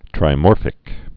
(trī-môrfĭk) also tri·mor·phous (-fəs)